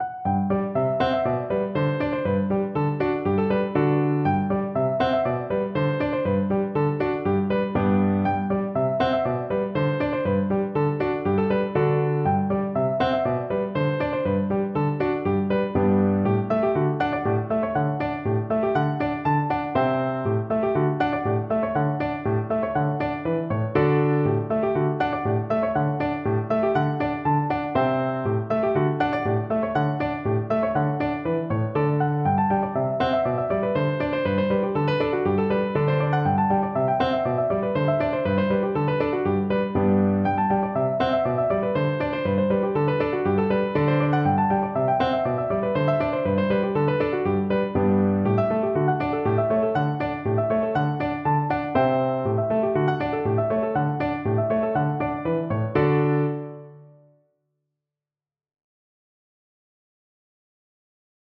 No parts available for this pieces as it is for solo piano.
4/4 (View more 4/4 Music)
Piano  (View more Intermediate Piano Music)
world (View more world Piano Music)